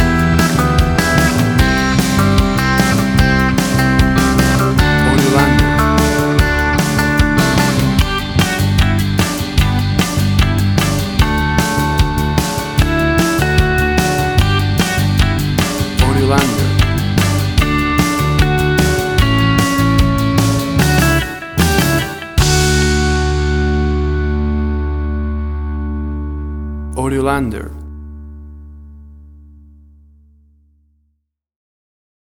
Tempo (BPM): 150